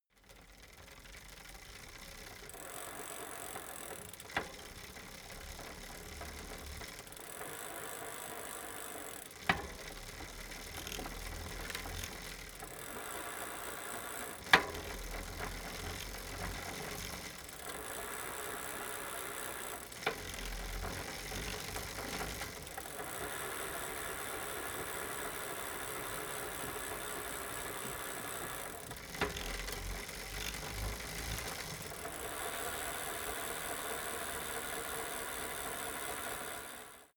Gemafreie Sounds: Fahrrad